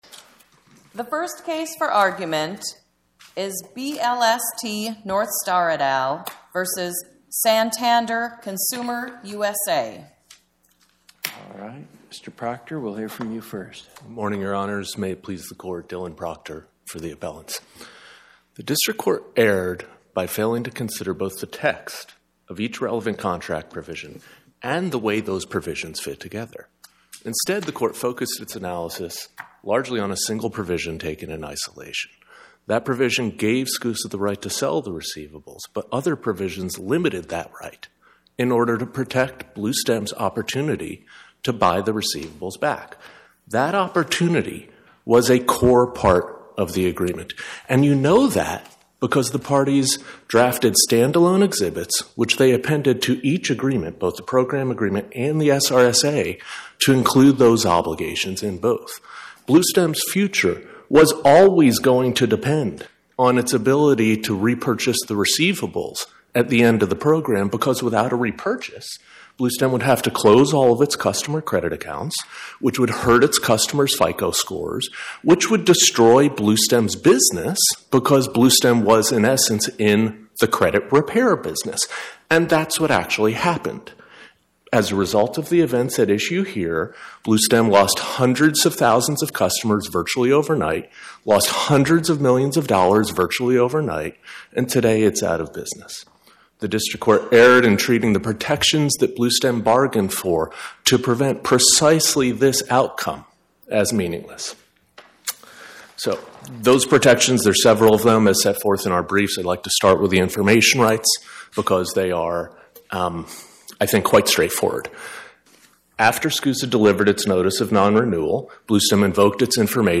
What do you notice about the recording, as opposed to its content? My Sentiment & Notes 24-3597: BLST Northstar, LLC vs Santander Consumer USA, Inc. Podcast: Oral Arguments from the Eighth Circuit U.S. Court of Appeals Published On: Thu Feb 12 2026 Description: Oral argument argued before the Eighth Circuit U.S. Court of Appeals on or about 02/12/2026